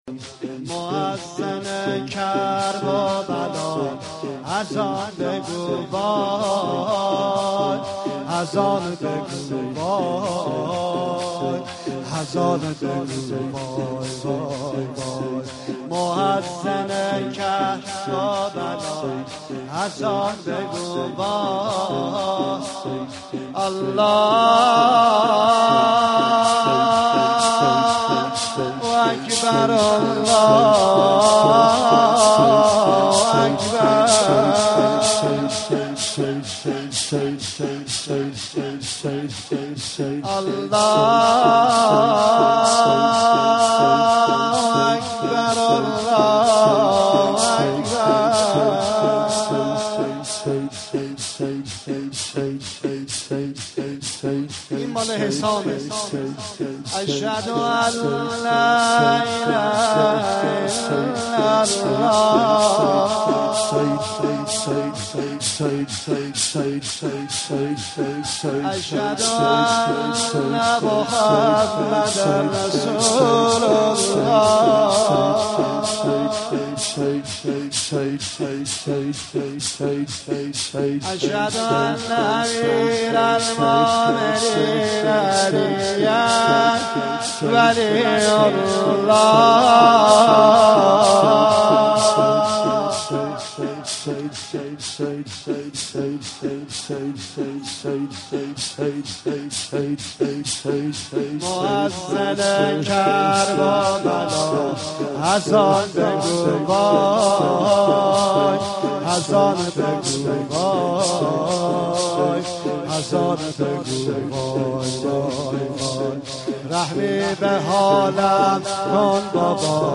اذان دلنشین
در وسط شور
در شب هشتم محرم 92